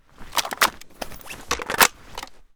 ak74_reload.ogg